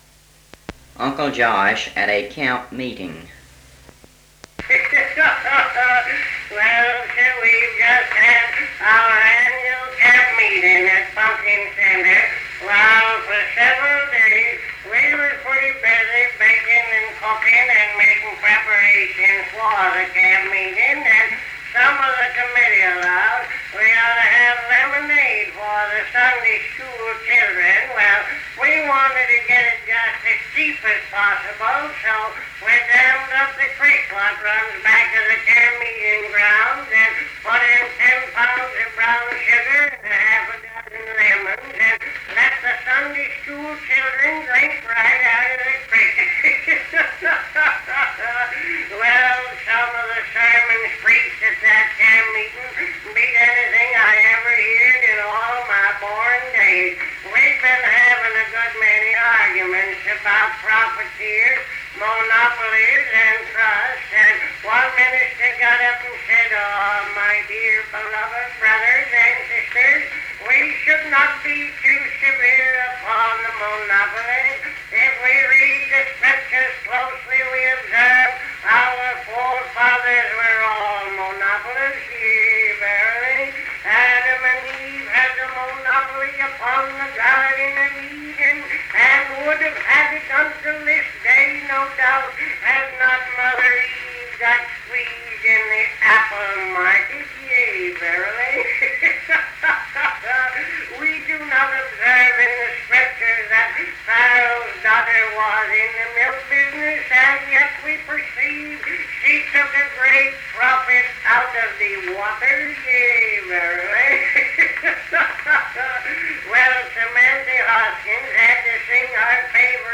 Cal Stewart's comedy routine, Uncle Josh at a camp meeting.